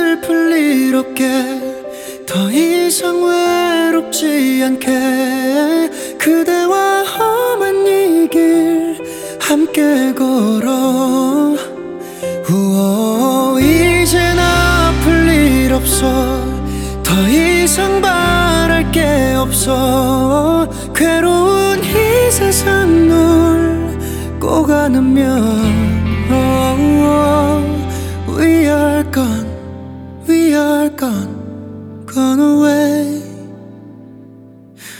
Жанр: Поп / Рок / K-pop